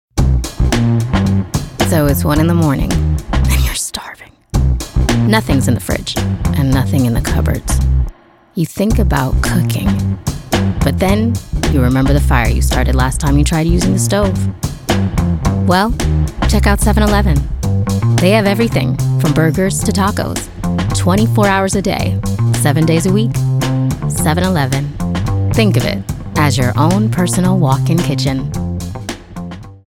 Voice Type:  Expressive, rich, conversational, with a touch of rasp.